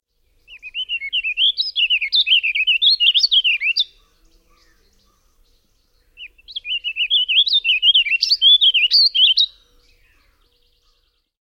typical Eastern song
• more “flowing” sing-song rhythm, with more consistent pitch and tempo
• successive phrases usually only slightly higher or lower than the preceding phrase, notice the gradual rise and fall of phrases throughout the song
• each phrase shorter, with shorter pauses between phrases, overall delivery more rapid
• averages lower-pitched and in a narrow range with most phrases between 2 and 4 kHz
• burry phrases used only occasionally
• overall sounds more uniform with all phrases similar
• usually ends with emphatic high note